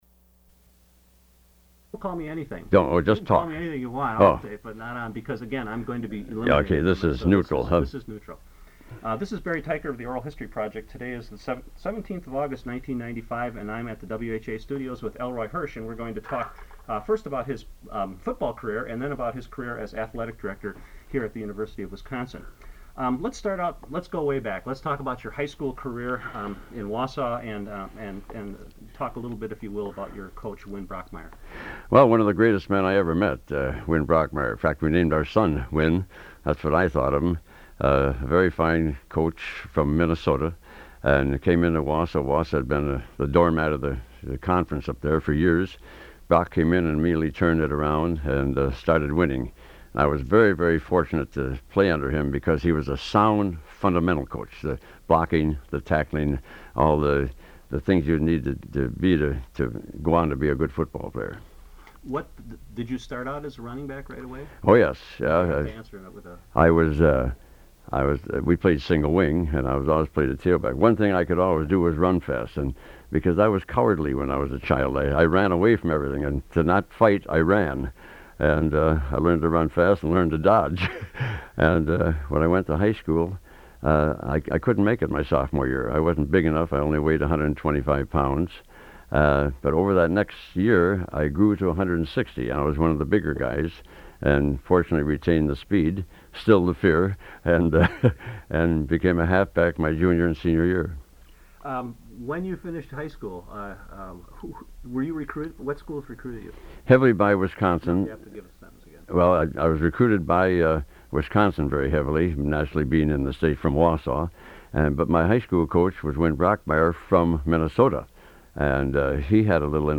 Oral History Interview: Elroy Hirsch (0440)